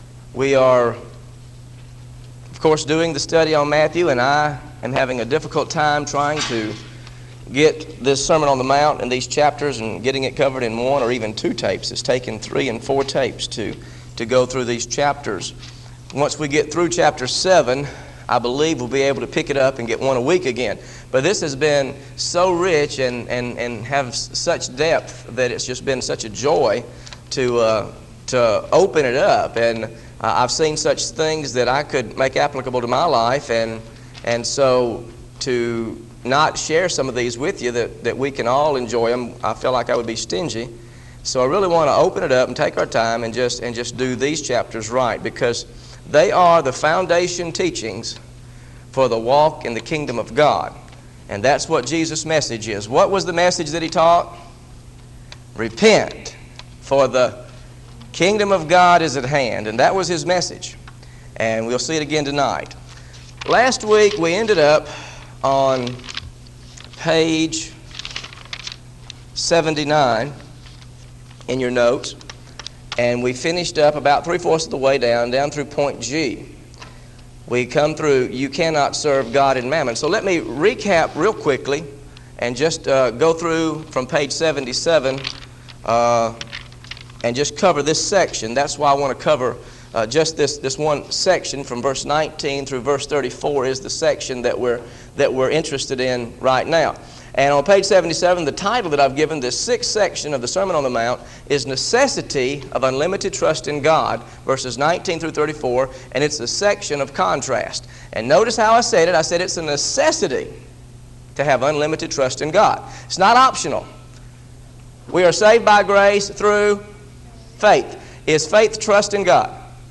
Sermon on the Mount Study